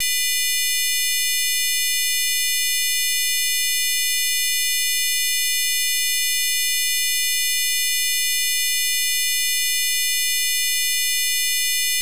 Bild 2, multiton med asymmetrisk olinjäritet motsvarande en andraton på -30db.